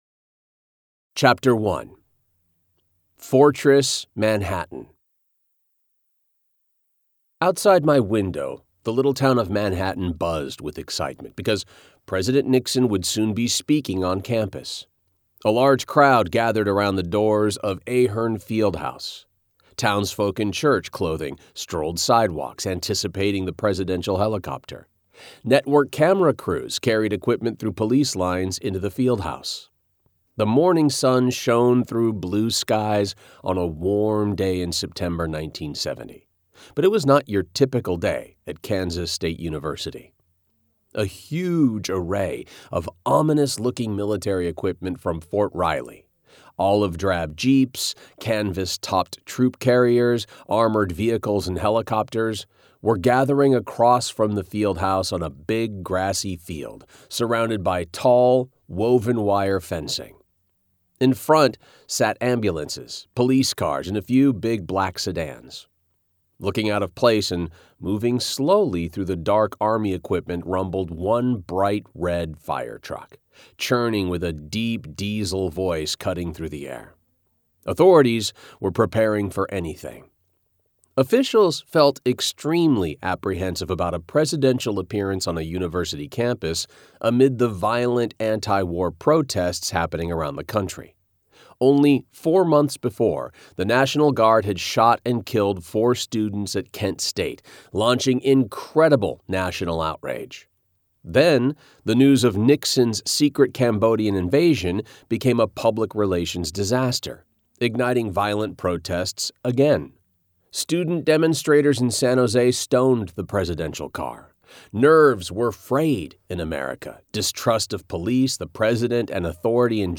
• Audiobook • 14 hrs, 15 mins
Genre: Memoir / Biography, Memoir / Biography